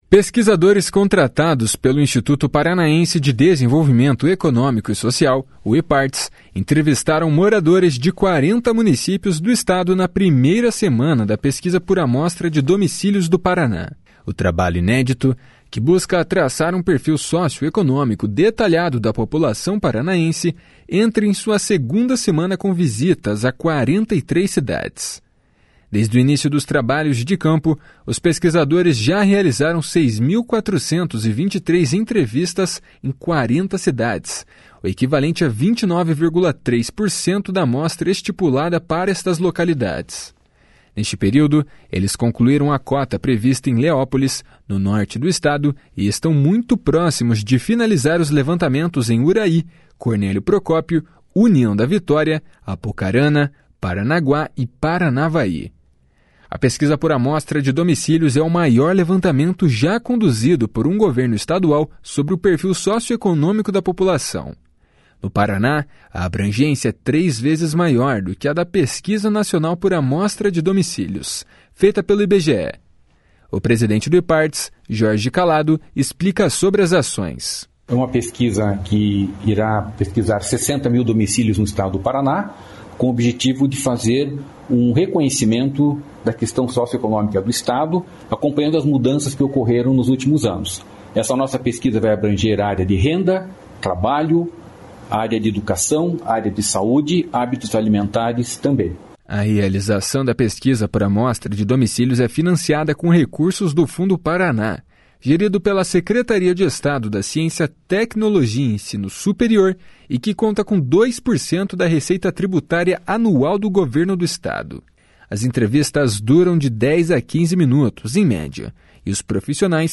com_6423_entrevistas_pesquisa_socioeconomica.mp3